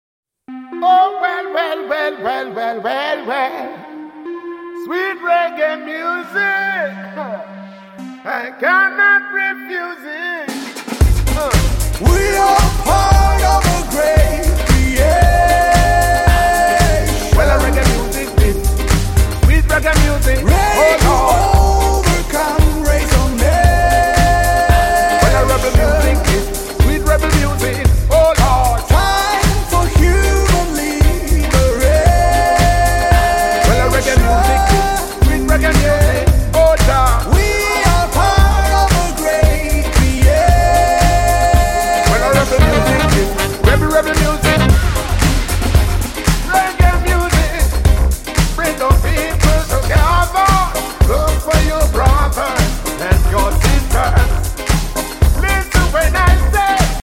European Pop
with with a slight African taste